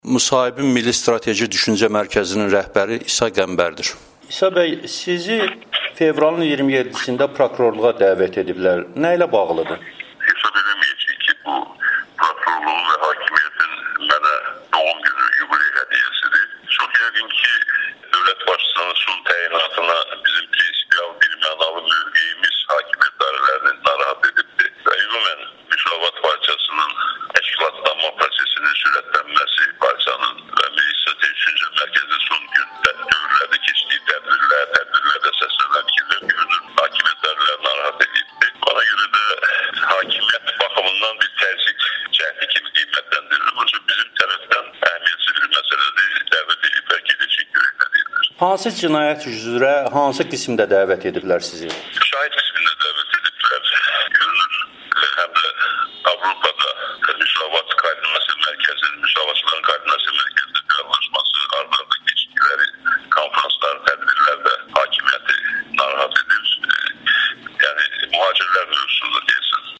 Milli Strateji Düşüncə Mərkəzinin sədri İsa Qəmbərin Amerikanın Səsinə müsahibəsi